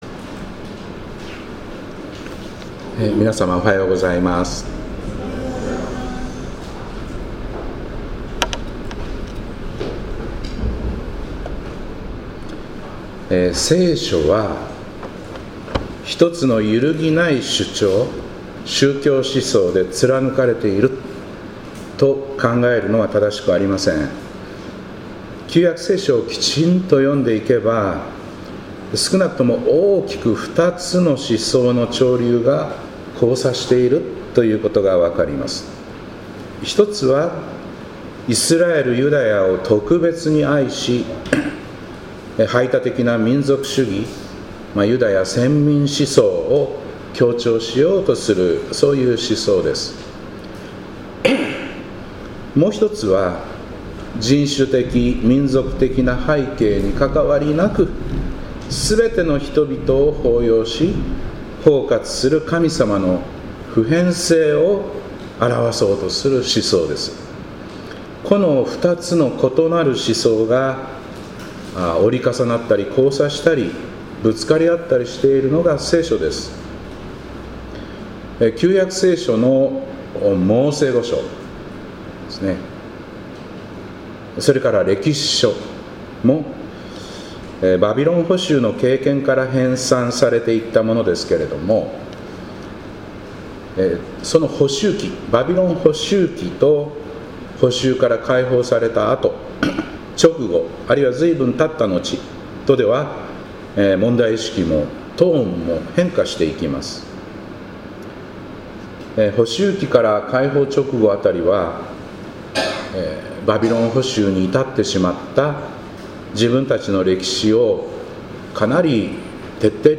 2025年10月19日礼拝「ヨナ的感情をどうするか」